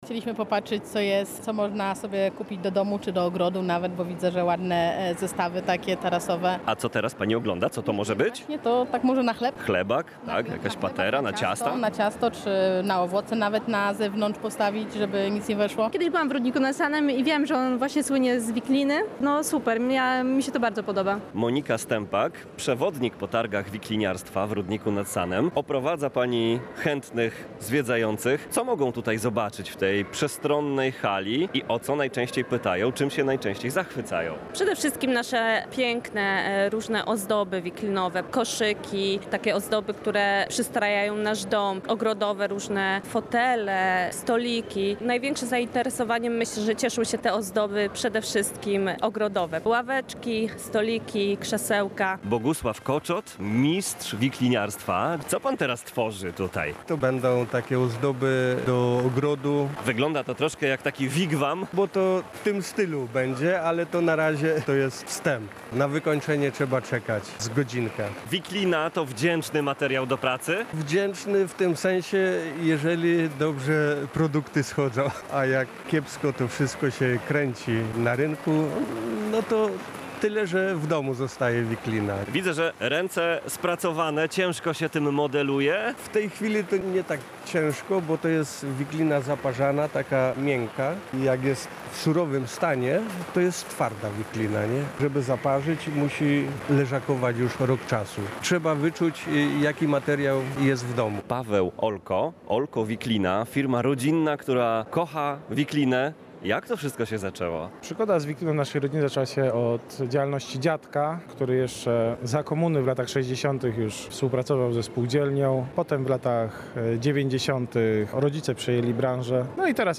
– Jesteśmy światową stolicą wikliniarstwa i tym wydarzeniem chcemy powiedzieć o tym światu, a jednocześnie dać mieszkańcom Rudnika i okolic okazję do przyjemnego spędzenia czasu – mówi o imprezie Waldemar Grochowski, burmistrz Rudnika nad Sanem.
Relacja